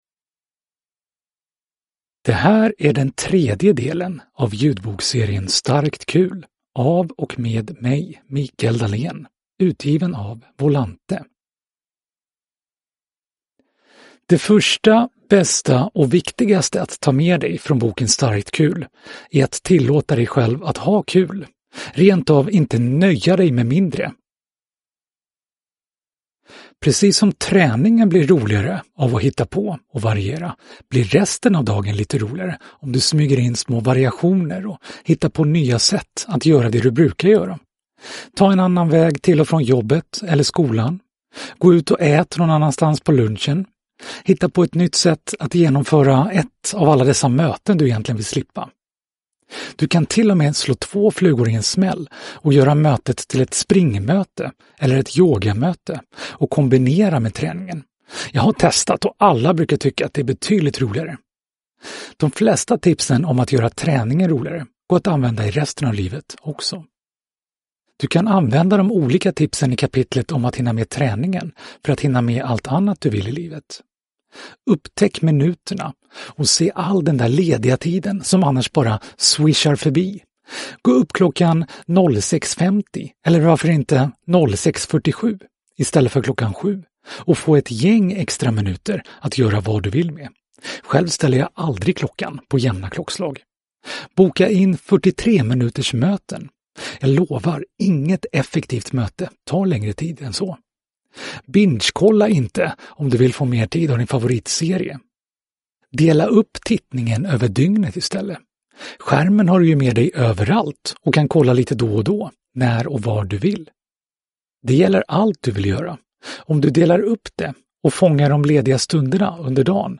Micael Dahlen berättar personligt i denna ljudboksversion av tankeboken Starkt kul.